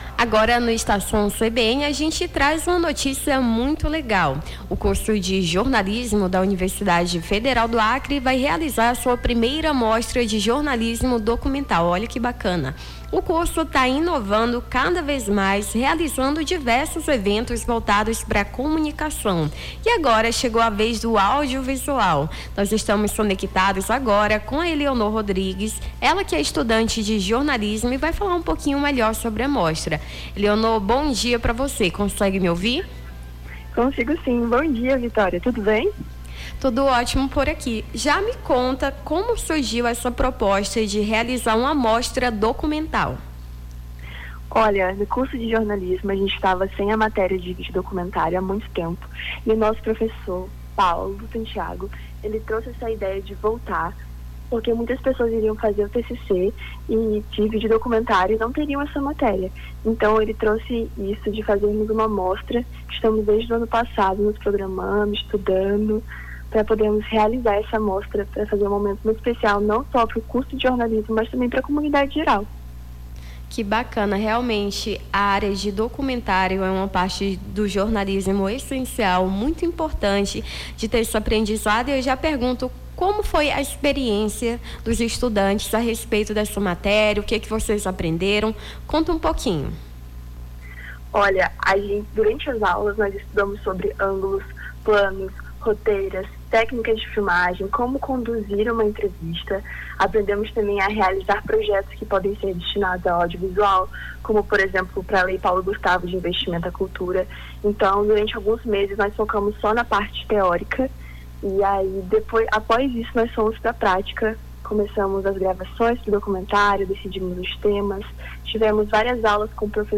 Nome do Artista - CENSURA- (ENTREVISTA MOSTRA DOCUMENTÁRIO) 05-04-25.mp3